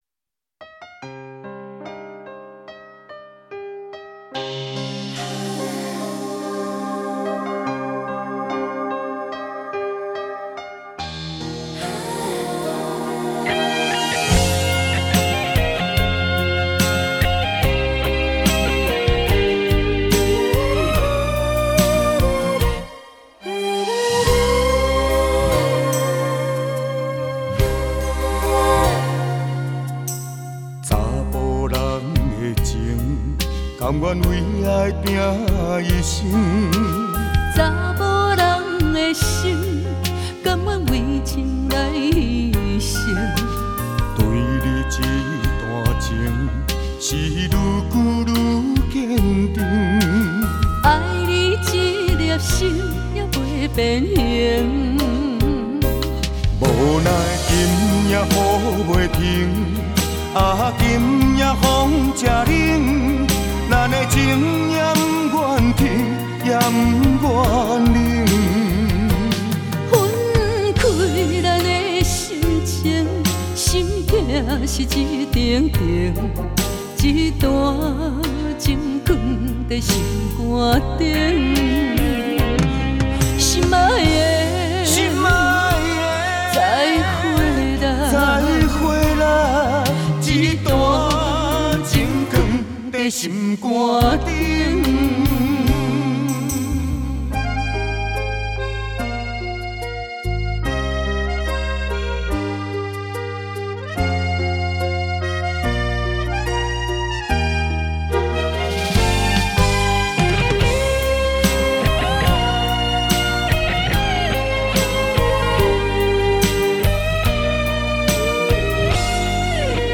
音乐类型：台语
动人的情歌，让人更感窝心